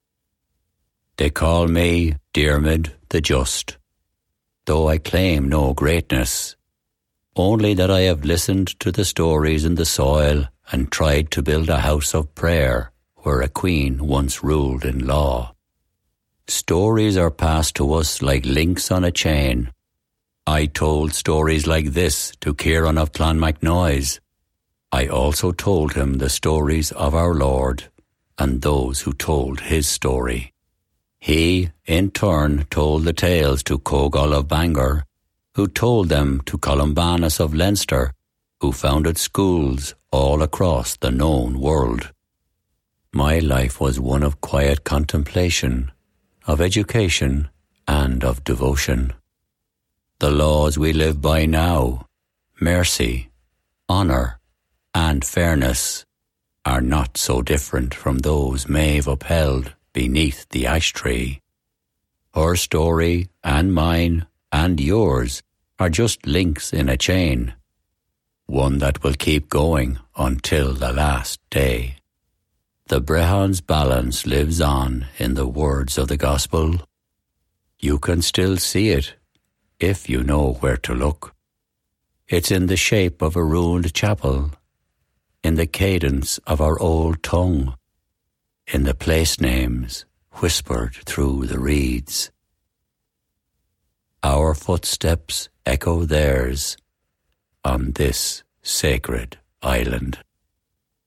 English (Irish)
🎙 Warm, authentic Irish voice with depth, clarity and versatility
• Character voices and warm, natural conversational reads
• Broadcast-quality sound